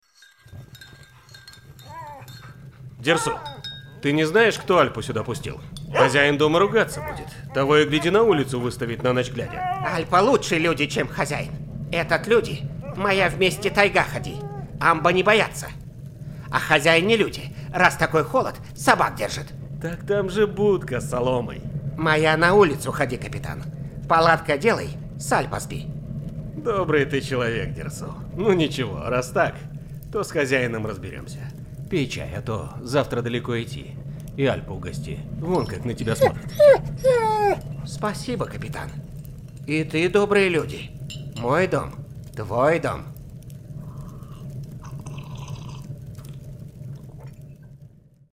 Тракт: Shure sm7b,AT-4040 Long Voice Master, RME BabyfacePRO-FS